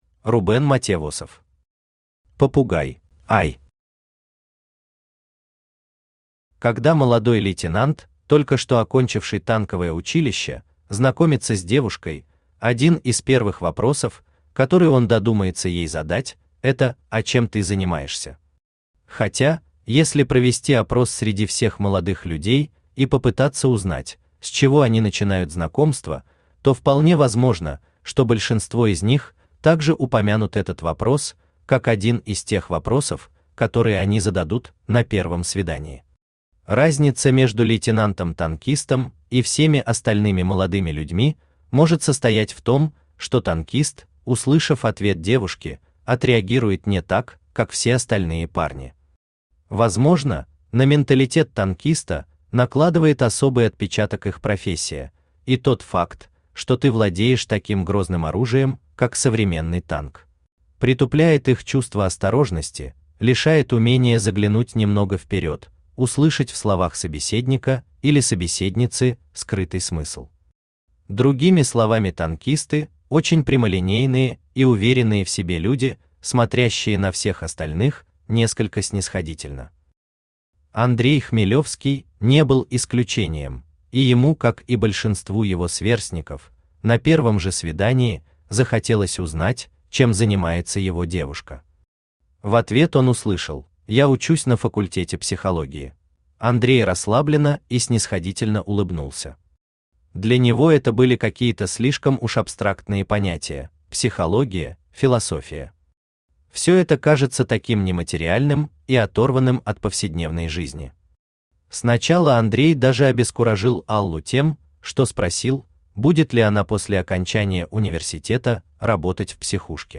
Аудиокнига Попугай | Библиотека аудиокниг
Aудиокнига Попугай Автор Рубен Матевосов Читает аудиокнигу Авточтец ЛитРес.